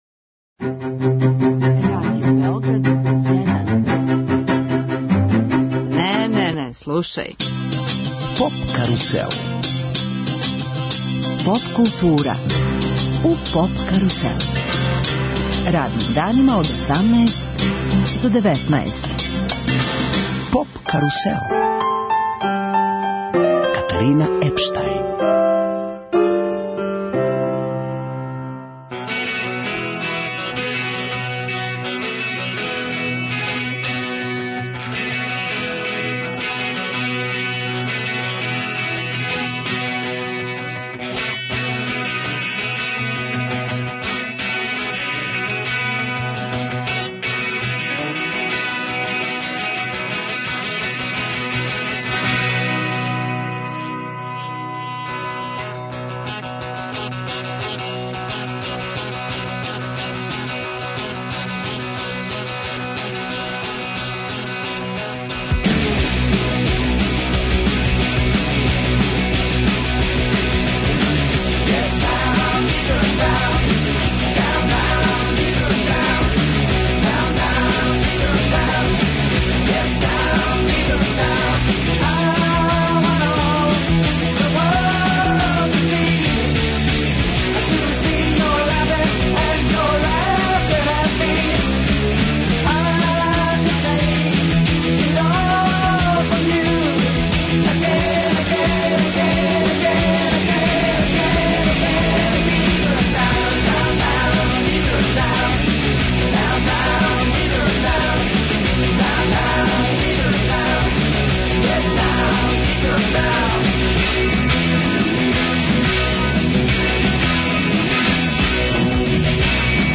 Гост емисије је композитор, гитариста, певач и продуцент